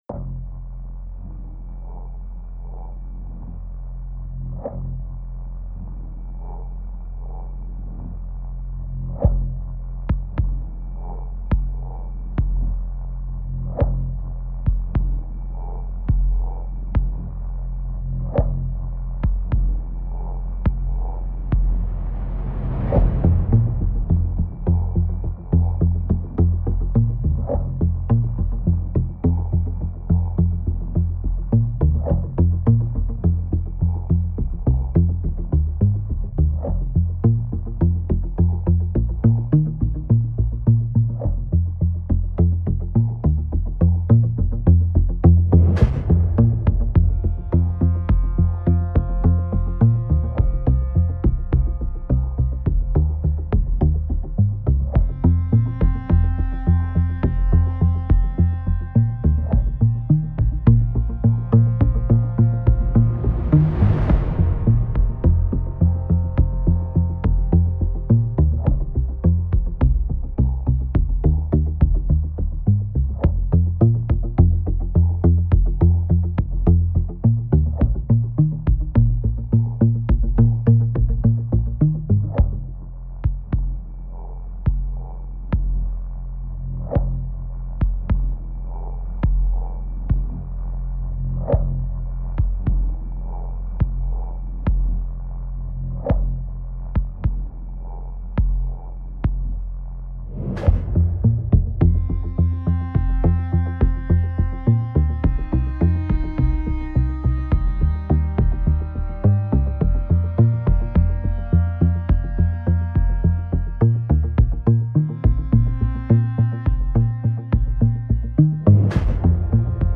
cool
repetitiv
nervös
geheimnisvoll
Drums
dissonant
elektronisch
Electro